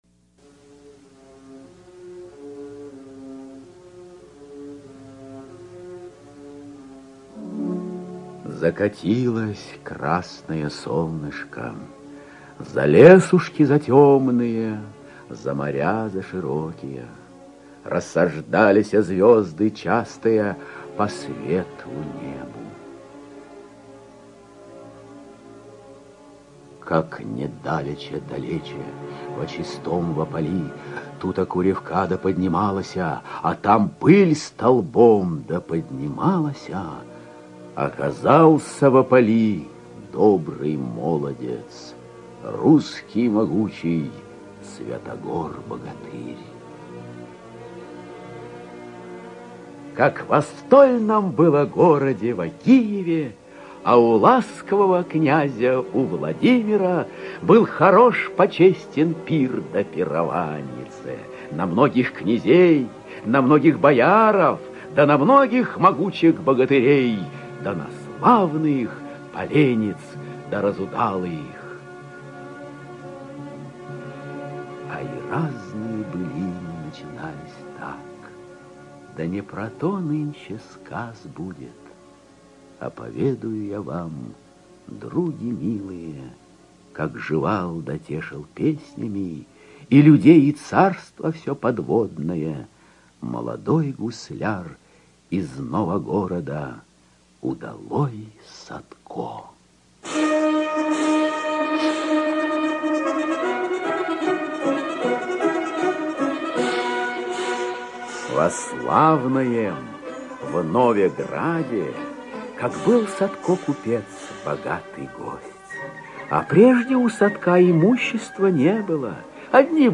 Садко - аудиосказка про гусляра - слушать онлайн